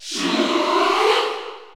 List of crowd cheers (SSBU)/French
Shulk_Cheer_French_PAL_SSBU.ogg